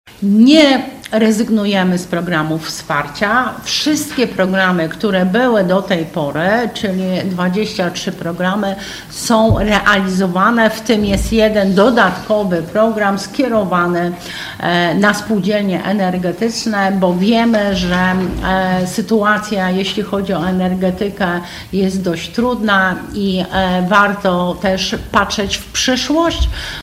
Zaprezentowała je na dzisiejszej konferencji prasowej członkini zarządu woj. mazowieckiego, Janina Ewa Orzełowska: